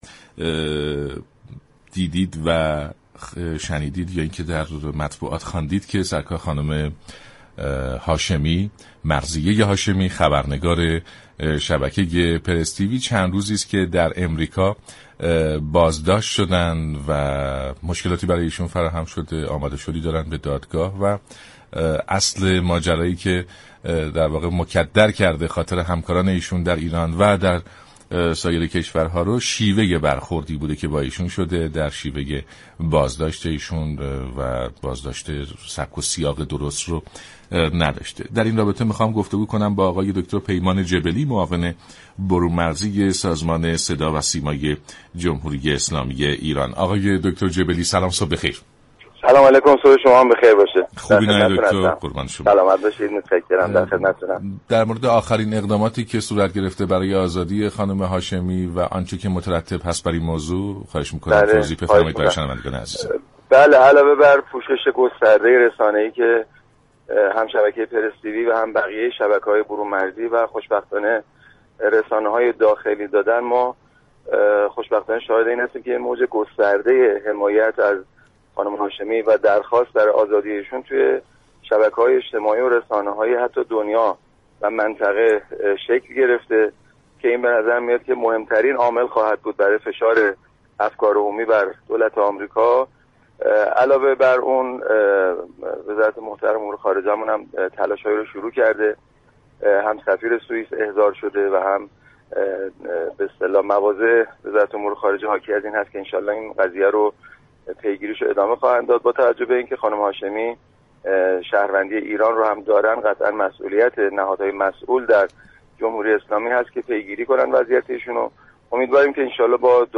«دكتر پیمان جبلی» معاون برون مرزی سازمان صدا و سیما در برنامه «سلام صبح بخیر» رادیو ایران گفت : وزارت خارجه اقدامات دیپلماتیك را برای آزادی خبرنگار شبكه پرس تی وی در دستور كار دارد